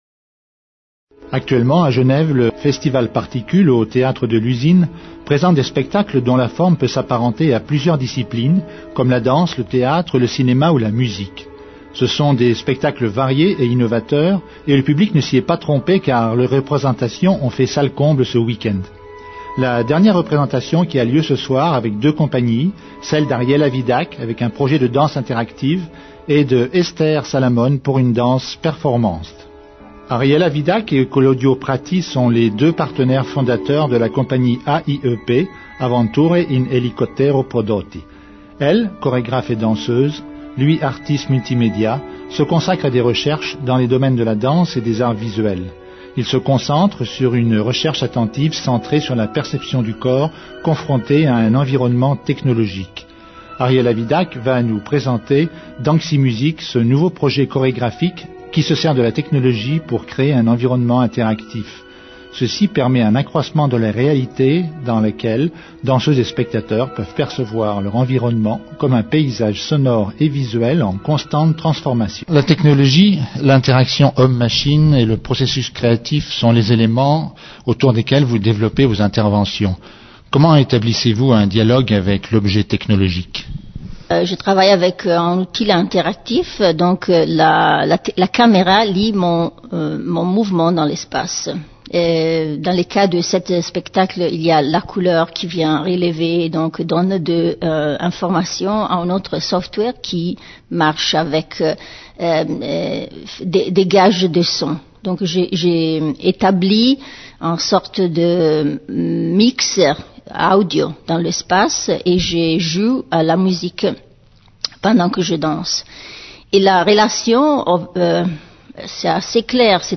Emission Cité Culture, du lundi au vendredi de 16h à 17h Radio Cité, Genève.